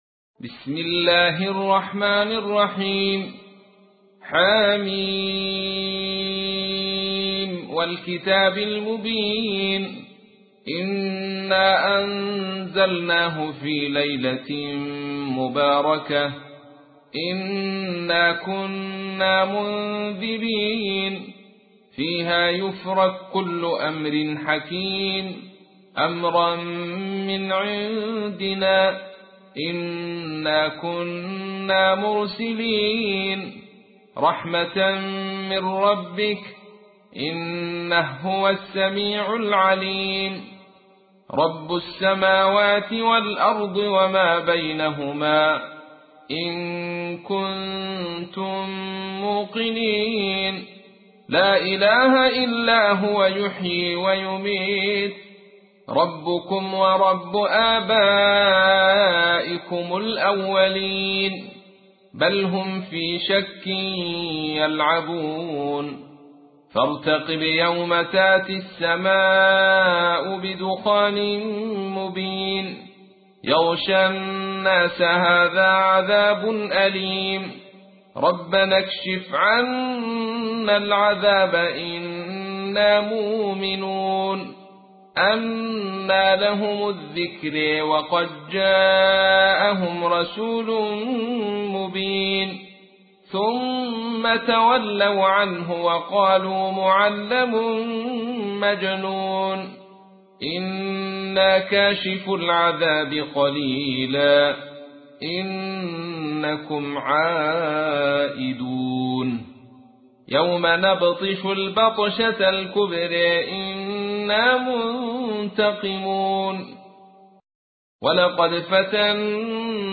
تحميل : 44. سورة الدخان / القارئ عبد الرشيد صوفي / القرآن الكريم / موقع يا حسين